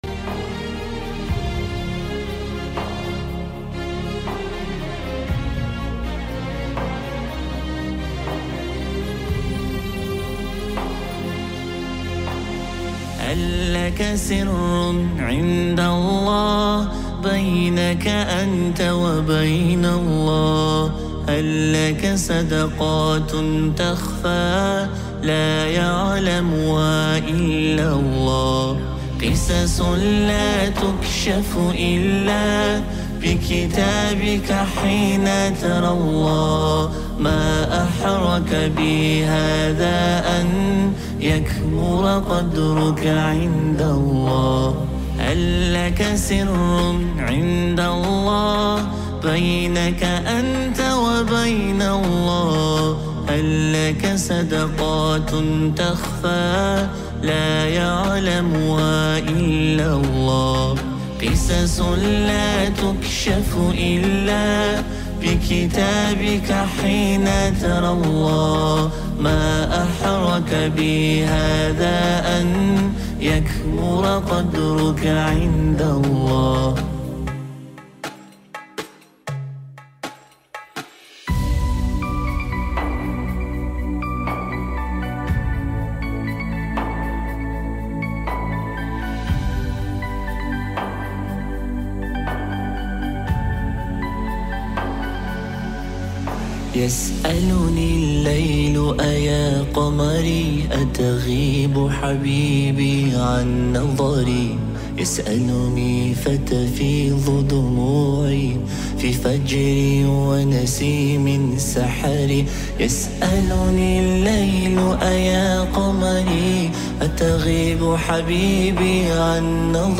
Arabic nasheed